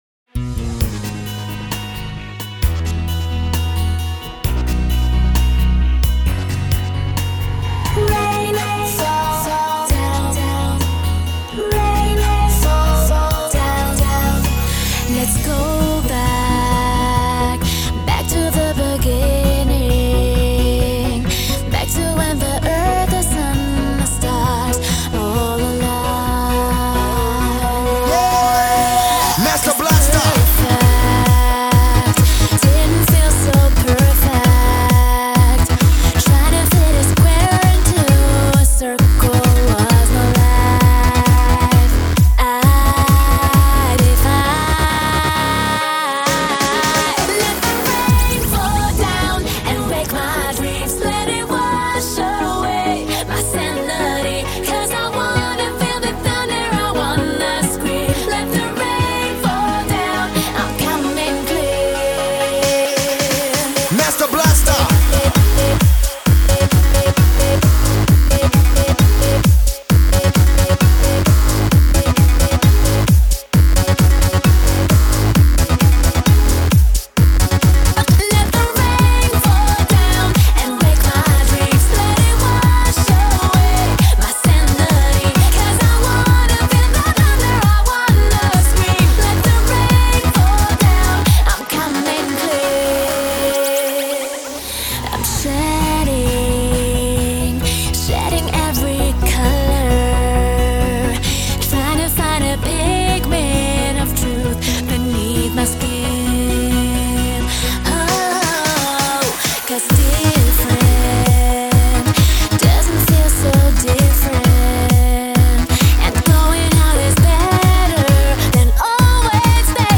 ClubMix [37]